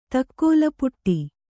♪ takkōlapuṭṭi